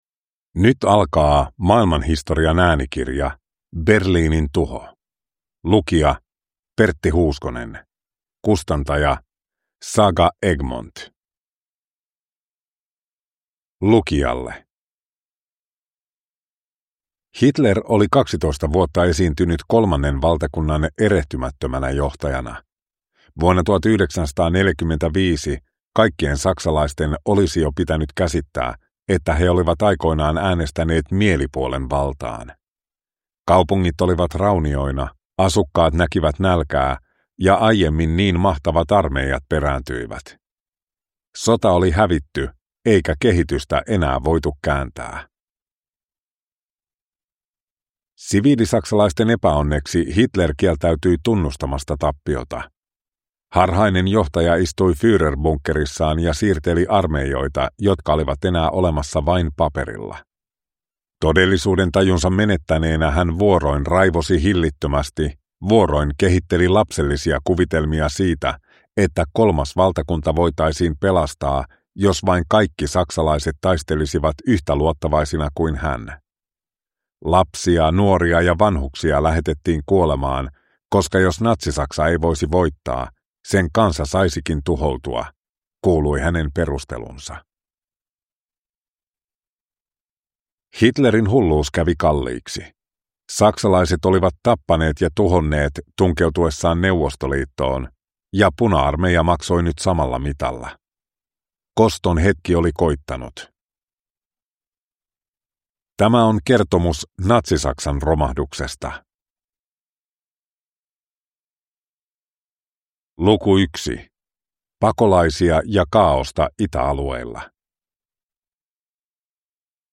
Berliinin tuho – Ljudbok